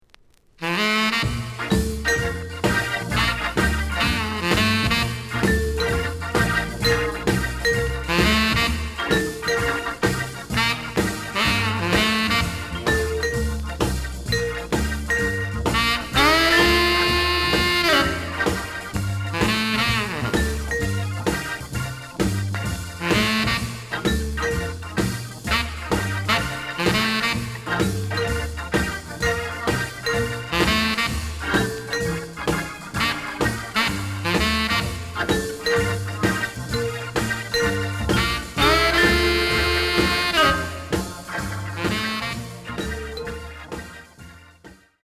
The audio sample is recorded from the actual item.
●Genre: Rhythm And Blues / Rock 'n' Roll
Some damage on both side labels. Plays good.)